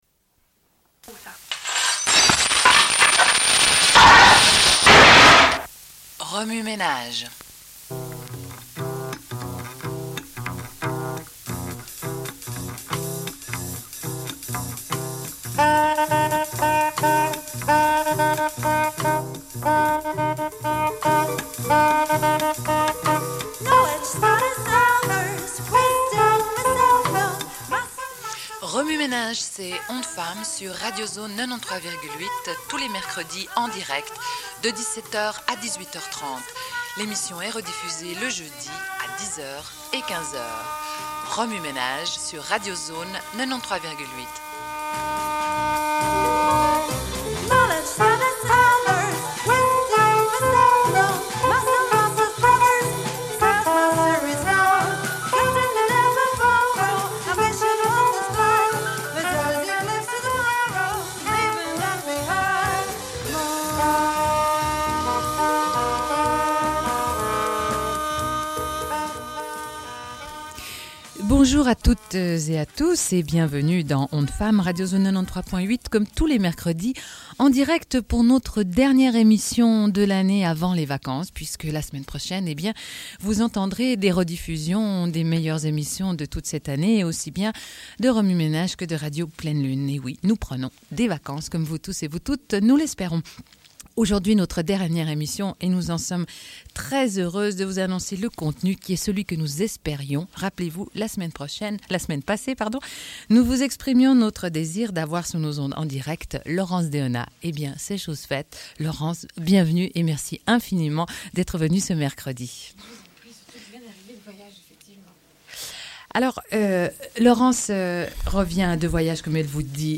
Une cassette audio, face A00:31:20